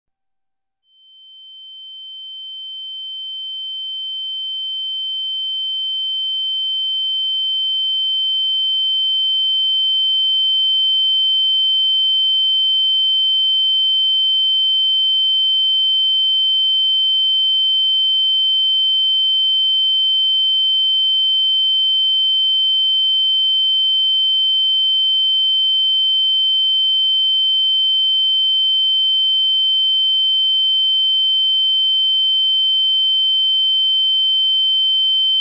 ear_ringing.ogg